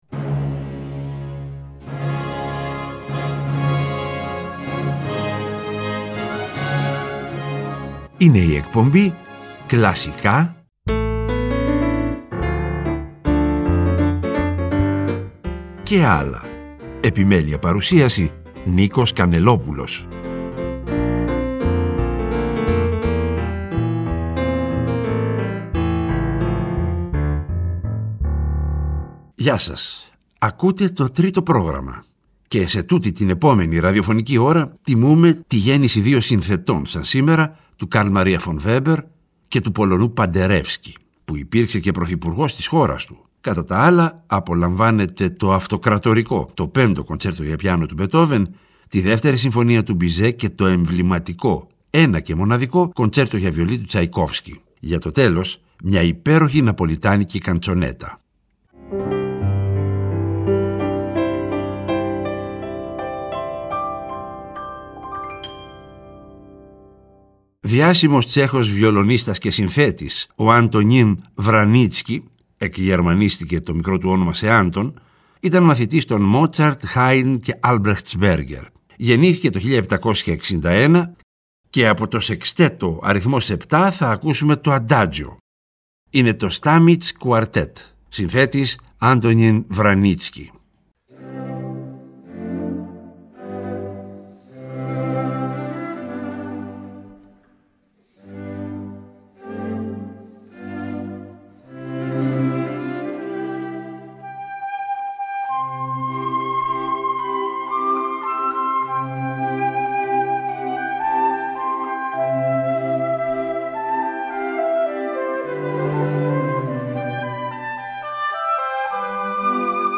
Βέμπερ, Παντερέφσκι, Τσαϊκόφκσι, Μπετόβεν, Μπιζέ αλλά και …καντσονέτες.
Τιμούμε τη γέννηση δύο συνθετών, του Καρλ Μαρία φον Βέμπερ (18 ή 19/11/1786) και του Πολωνού Παντερέφσκι (1860), που υπήρξε και Πρωθυπουργός της χώρας του. Κατά τα άλλα, απολαύστε το «Αυτοκρατορικό» «5ο Κοντσέρτο για Πιάνο» του Μπετόβεν, τη «2η Συμφωνία» του Μπιζέ και το εμβληματικό, ένα και μοναδικό, «Κοντσέρτο για Βιολί» του Τσαϊκόφσκι. Για το τέλος, μια υπέροχη ναπολιτάνικη καντσονέτα.